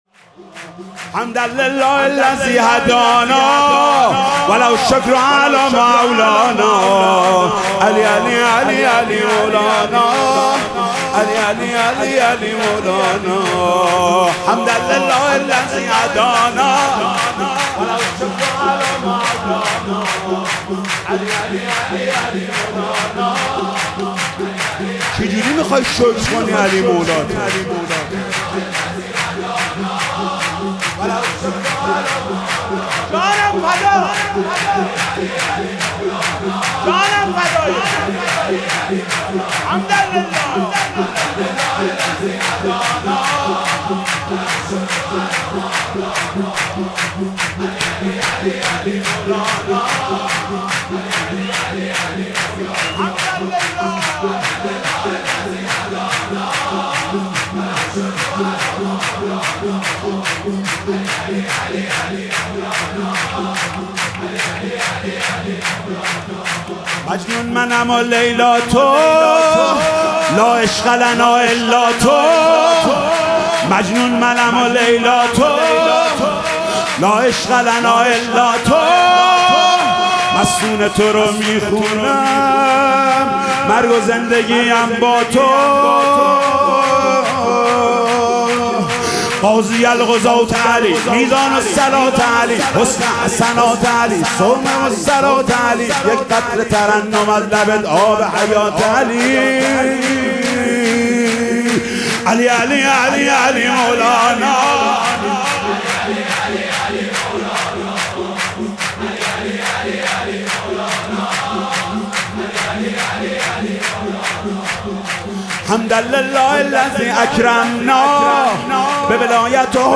سرود مولودی شور شب ولادت حضرت امیرالمومنین امام علی (ع)